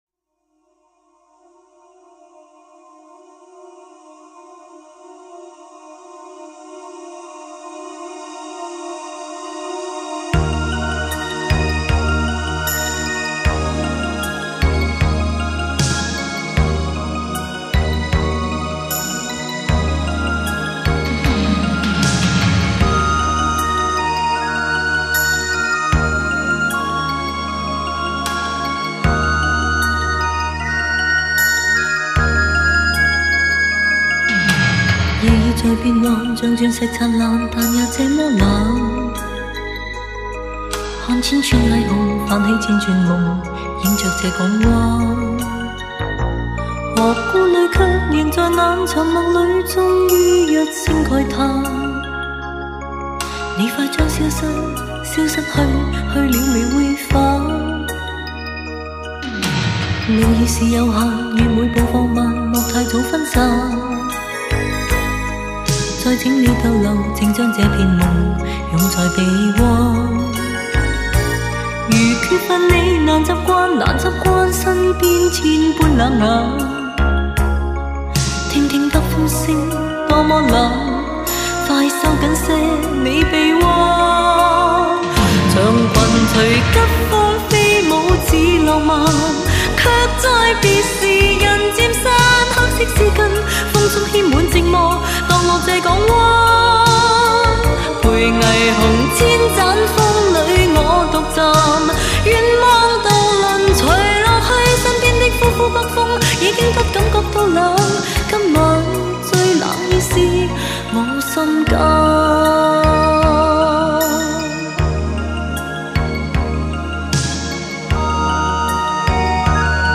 专辑语言：粤语